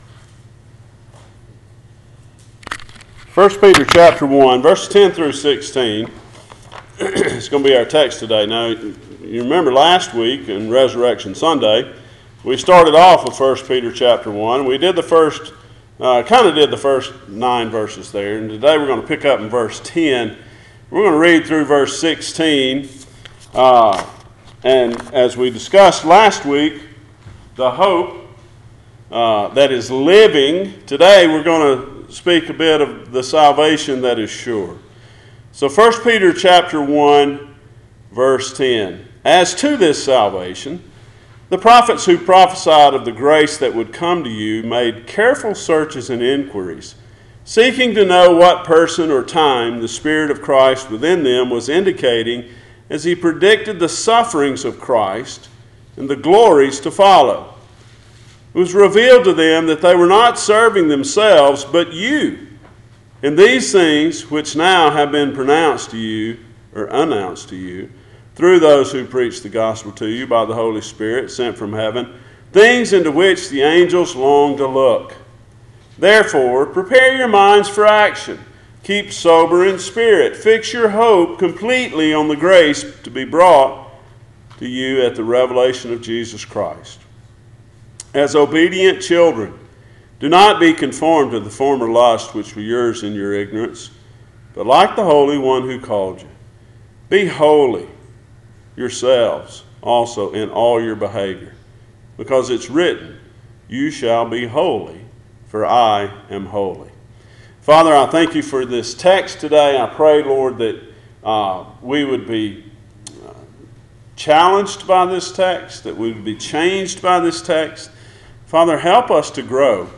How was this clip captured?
Sure Salvation Passage: 1 Peter 1:10-16 Service Type: Sunday Morning Topics